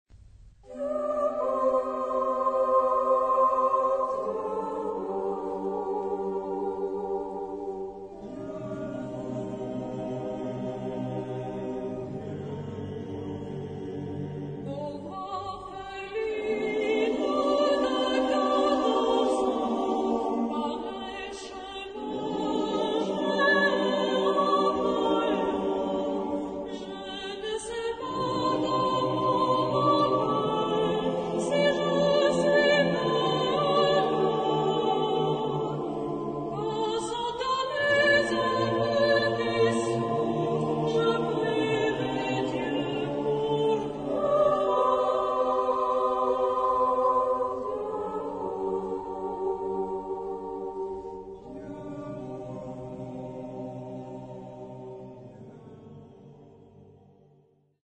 Genre-Stil-Form: Volkstümlich ; weltlich
Charakter des Stückes: mäßig ; ausdrucksvoll
Chorgattung: SATB  (4 gemischter Chor Stimmen )
Tonart(en): g-moll